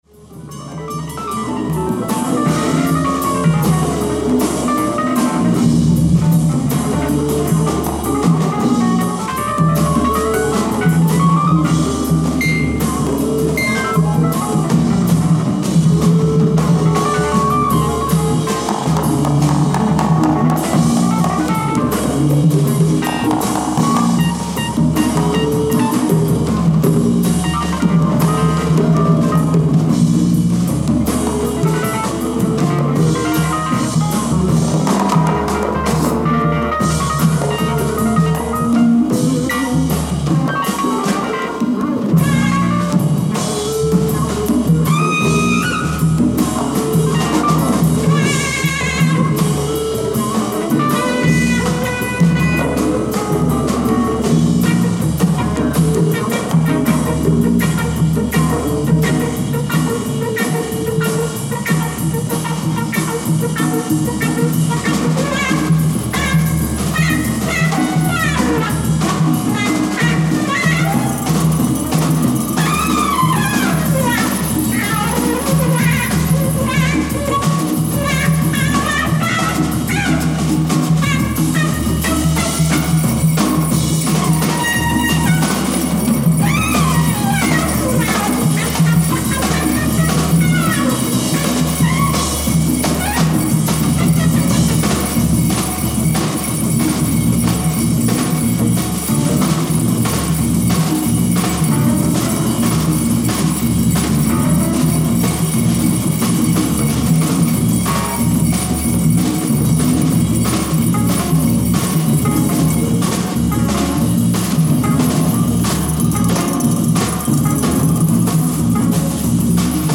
ライブ・アット・ベオグラード、セルビア 11/03/1971
※試聴用に実際より音質を落としています。